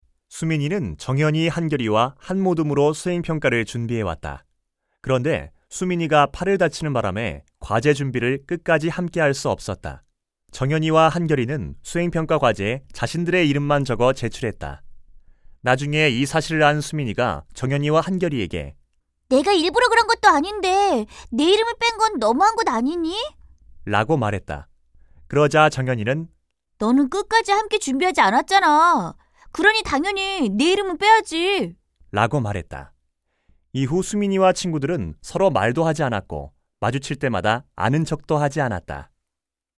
110쪽-내레이션.mp3